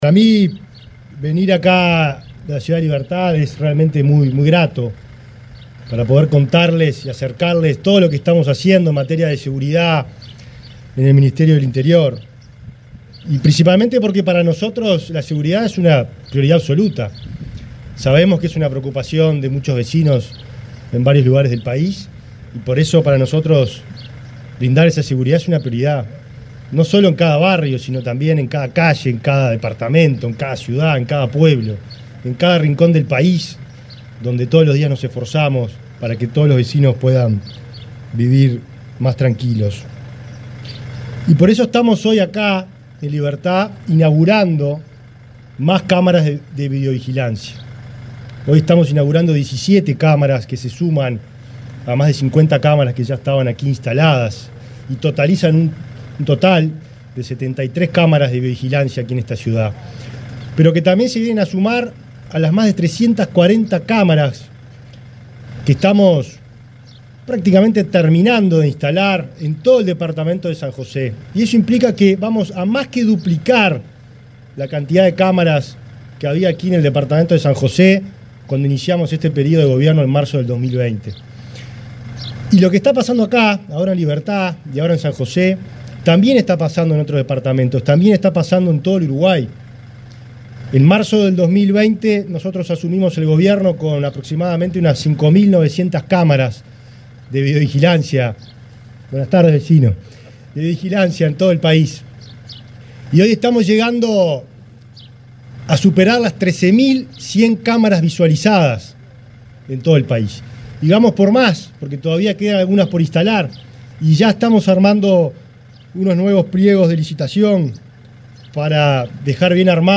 Palabras del ministro del Interior, Nicolás Martinelli
Palabras del ministro del Interior, Nicolás Martinelli 14/08/2024 Compartir Facebook X Copiar enlace WhatsApp LinkedIn El ministro del Interior, Nicolás Martinelli, participó de la presentación de las cámaras de videovigilancia, recientemente instaladas en la localidad de Libertad, departamento de San José.